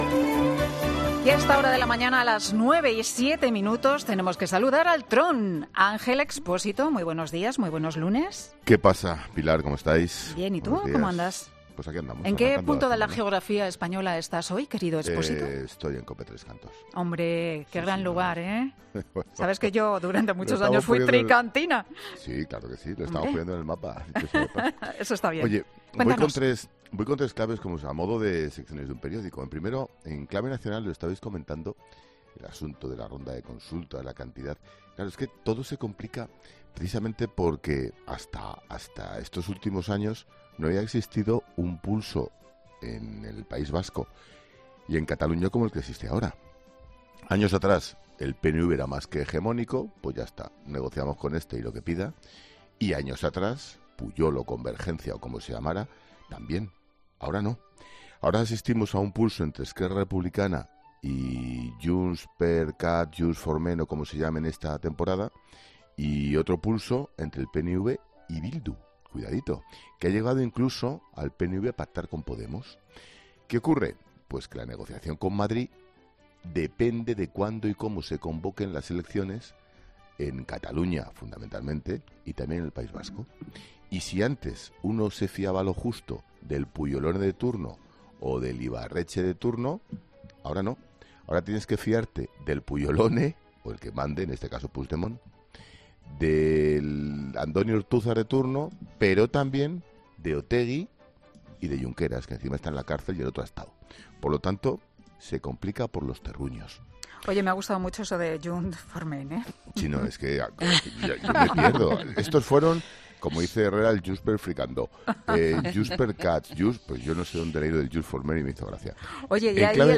Ángel Expósito analiza la actualidad del día en su "paseíllo" por los micrófonos de Carlos Herrera